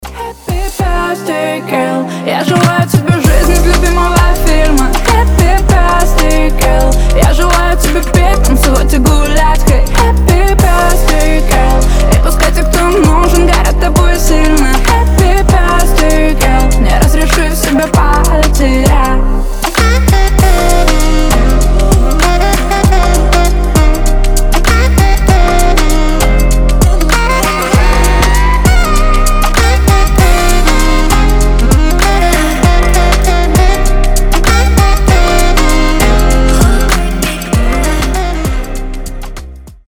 Саксофон
Позитивные , Поп